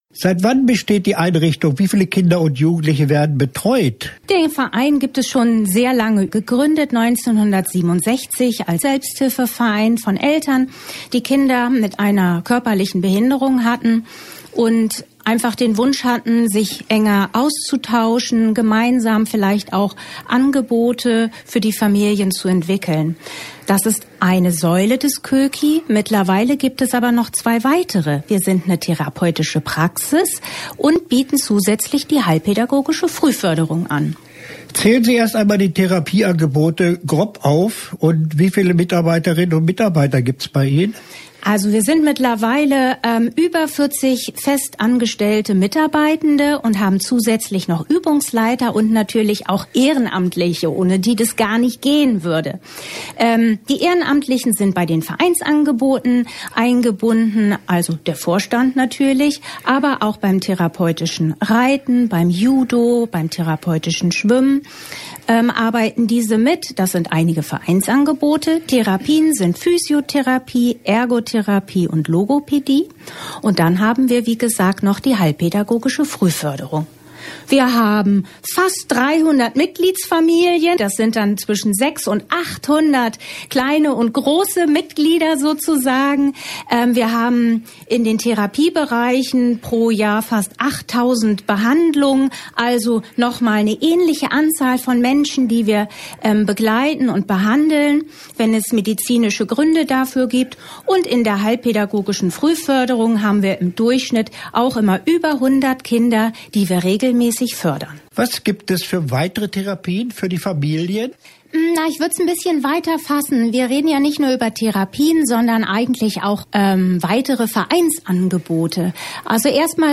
Interview-Koeki_wa.mp3